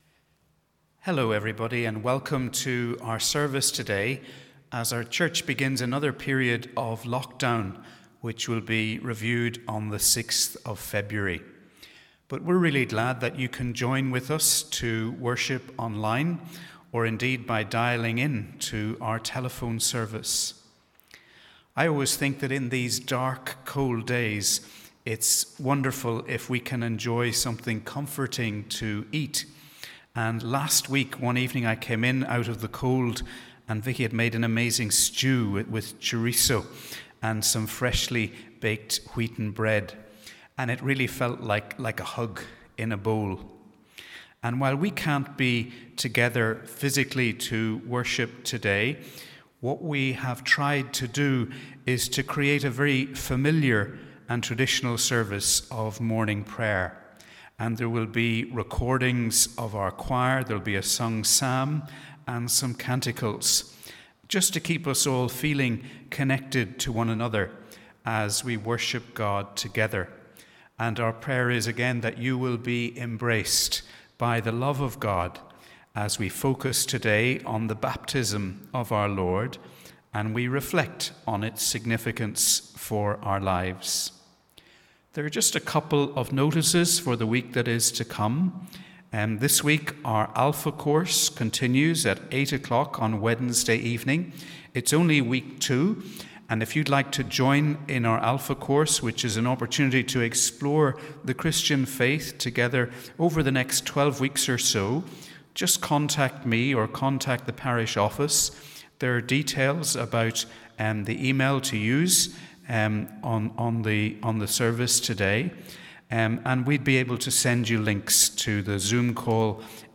Wherever you are today, we warmly welcome you to our service as our church begins another period of lockdown.